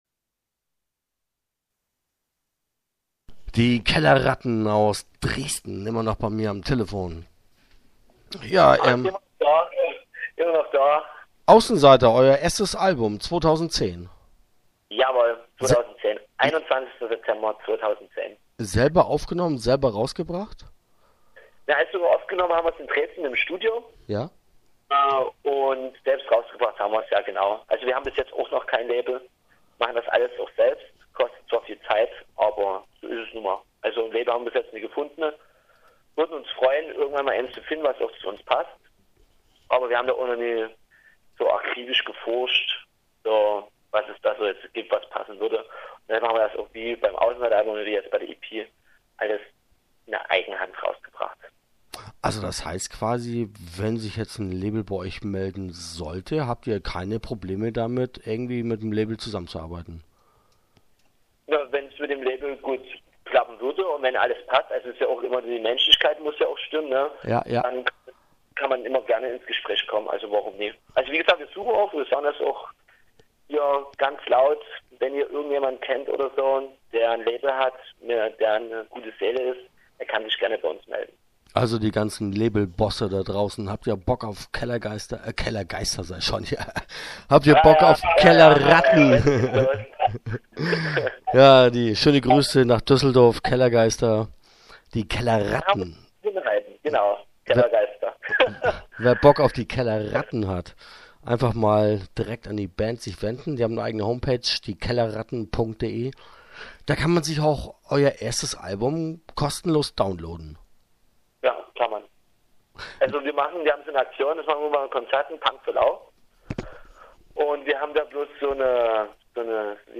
Start » Interviews » Die Kellerratten